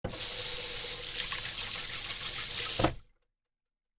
フリー効果音：手洗い
蛇口の水で手を洗う環境音のサンプリングしました！お風呂や家に帰ってきた時のシーンにぴったり！
washing.mp3